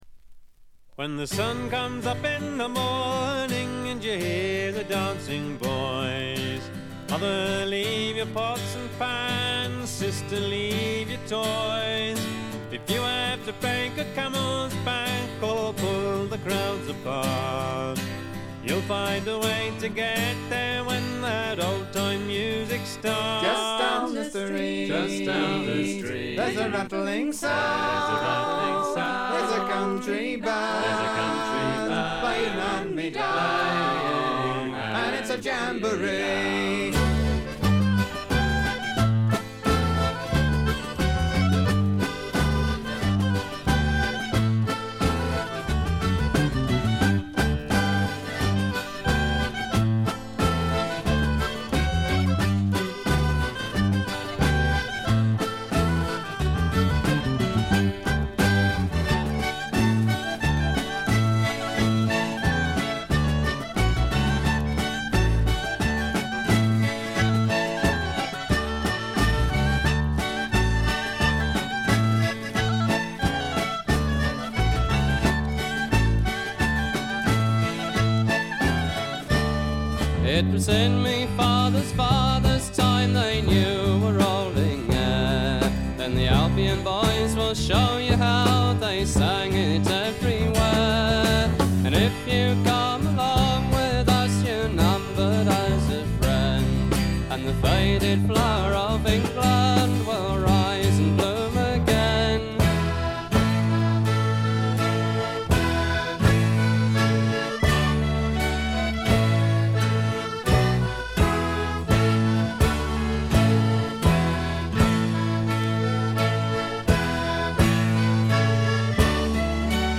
エレクトリック・トラッドの基本中の基本です。
試聴曲は現品からの取り込み音源です。